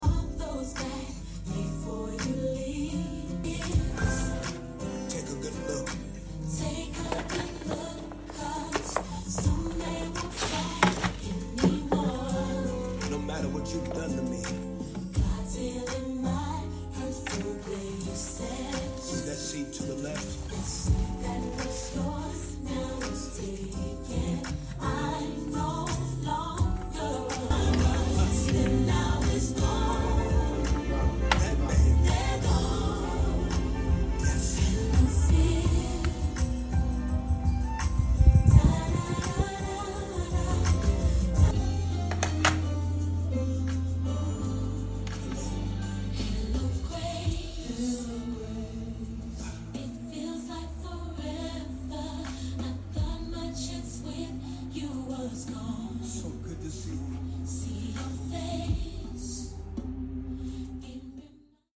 NEW UPGRADED OSCILLATION PEDESTAL 120 degree sound effects free download
With upgraded technology, the battery-operated standing fan is super silent even if at the largest speed.